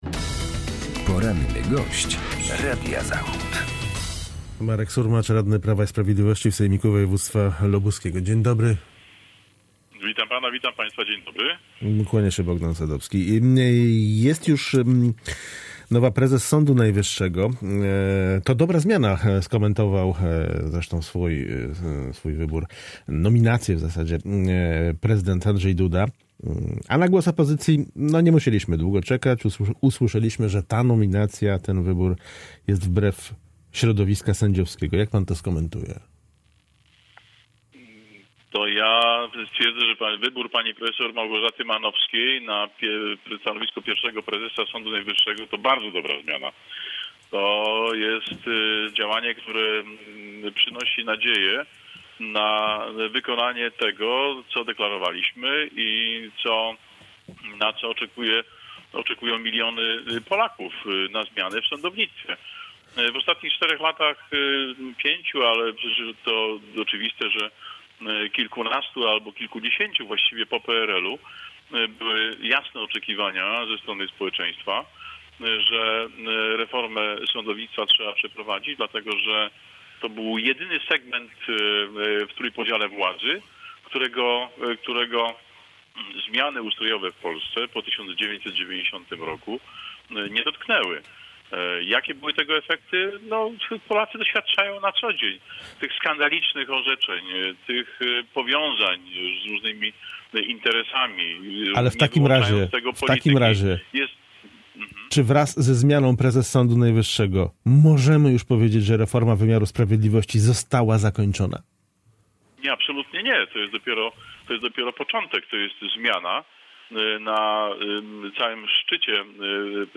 Z radnym sejmiku wojewódzkiego z klubu PiS rozmawia